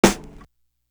Boing Snare.wav